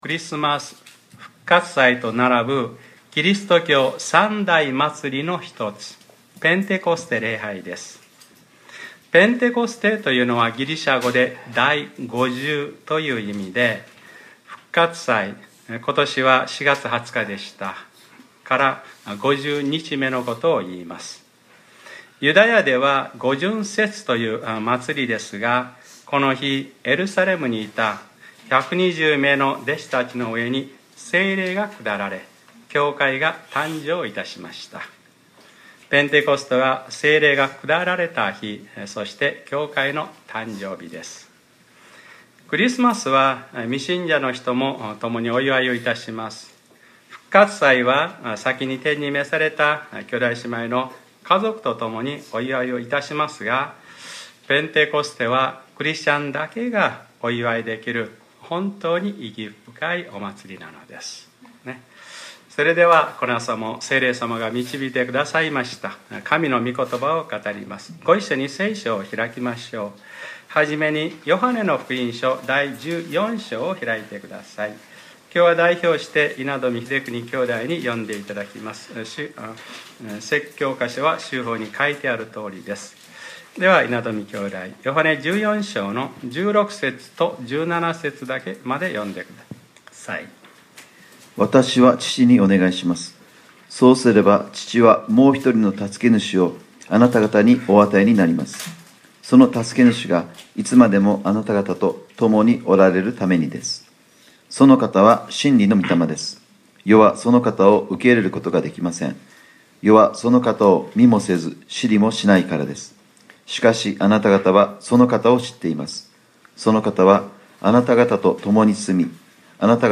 2014年6月 8日（日）礼拝説教 『ペンテコステ：その方（ペルソナ）は』 | クライストチャーチ久留米教会